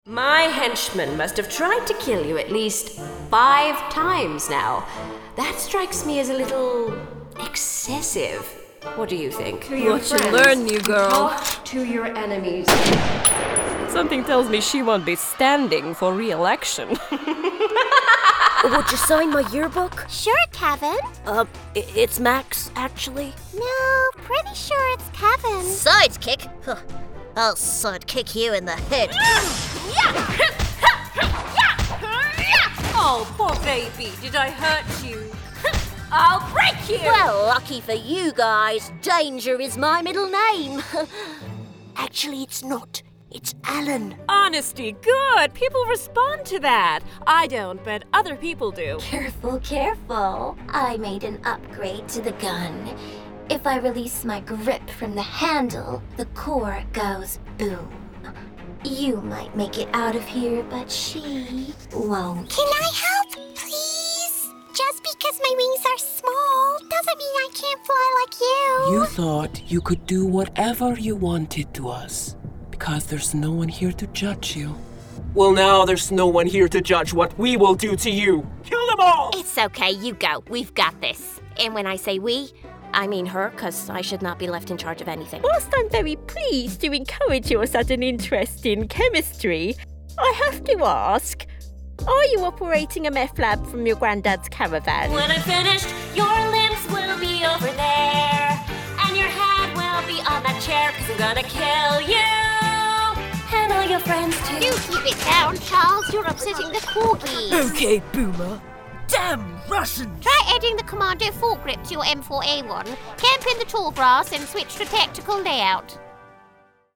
Engaging, Inspiring, Natural & Crystal Clear with Amazing Range & Versatility
Character & Animation Reel